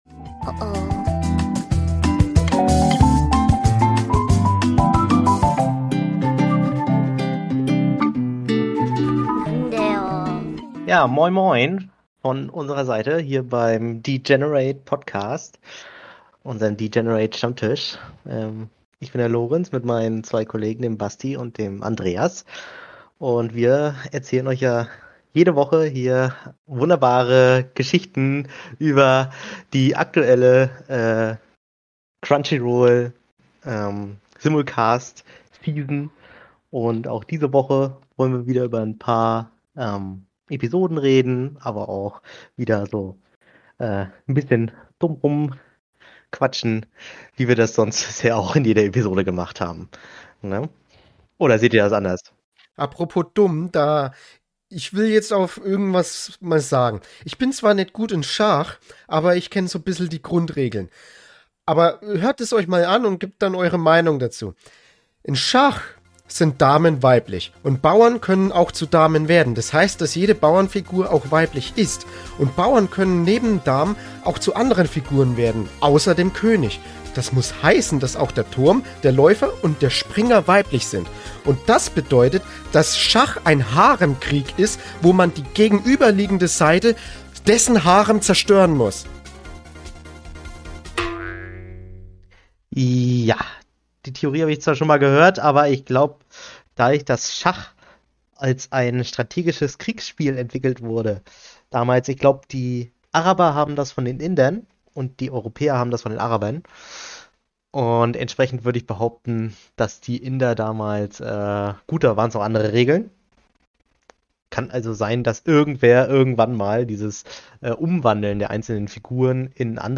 Neben dieser schachlichen Offenbarung reden unsere drei Studenten auch über ihren aktuellen Stand bei den Bachelorarbeiten, die ihnen momentan ordentlich Kopfzerbrechen bereiten. Und wie immer gibt’s auch einen tiefen Blick in die aktuelle Anime Simulcast Season, die erneut viel Gesprächsstoff liefert.